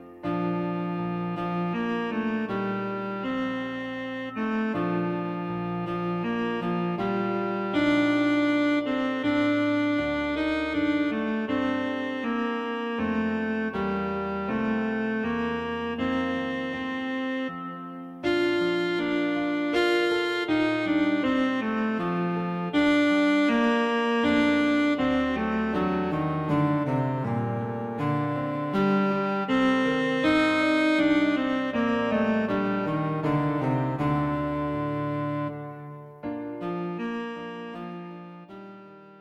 Cello and Piano
Three uplifting solos for Cello with Piano accompaniment
Aim for a warm to mellow texture with comforting sounds.
There is a general but slight crescendo throughout the piece